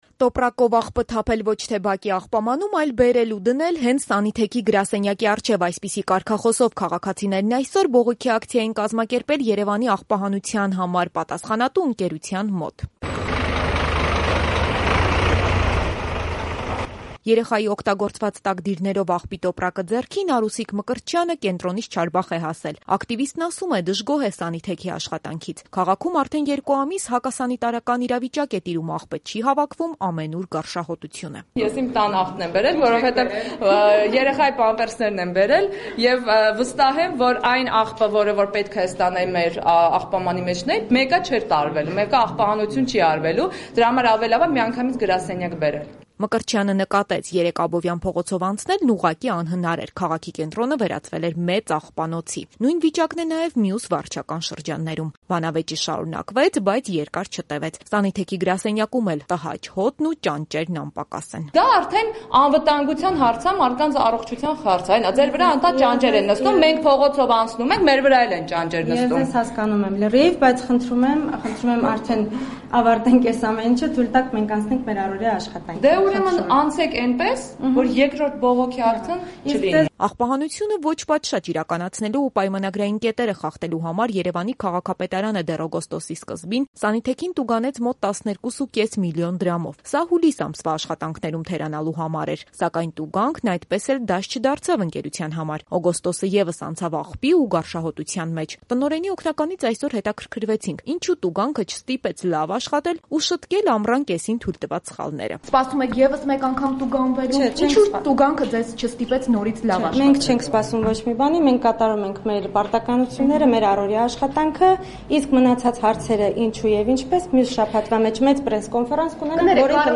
Բողոքի ակցիա «Սանիթեքի» գրասենյակի առջև